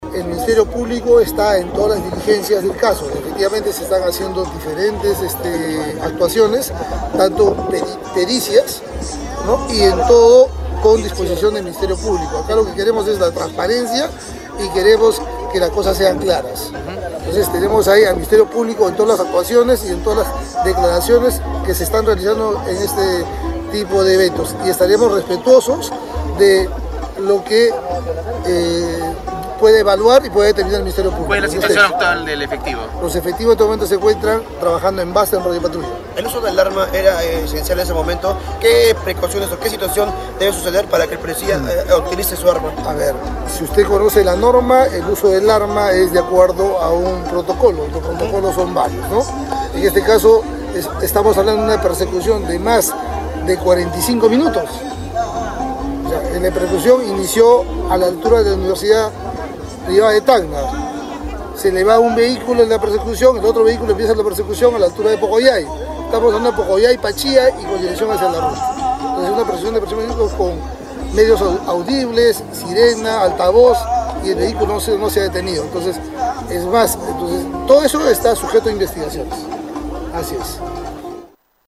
El Ministerio Público está en diligencias, están en marcha pericias «acá lo que queremos es la transparencia y que las cosas sean claras y estaremos respetuosos de lo que pueda determinar el Ministerio Público«, indicó el general PNP Arturo Valverde acerca de las investigaciones que Fiscalía realiza como homicidio simple entorno a la persecución policial que terminó en muerte el 28 de mayo.